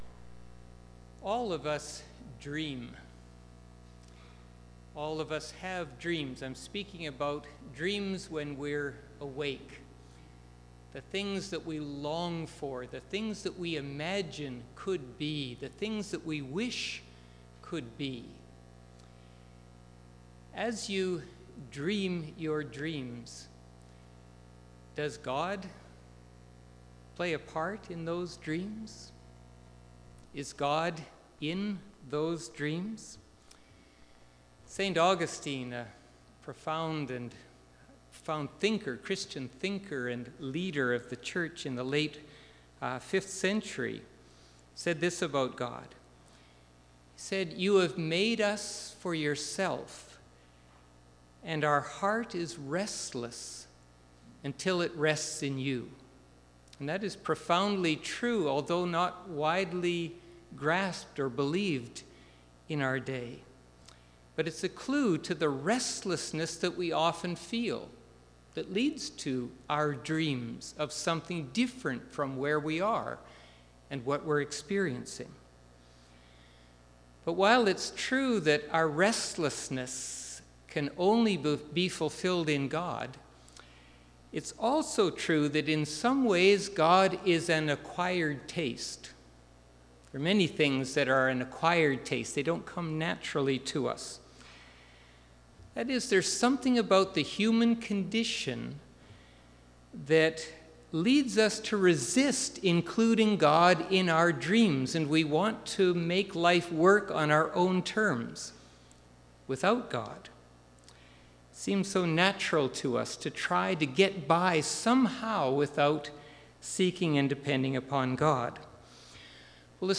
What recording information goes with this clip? Argyle Road Baptist Church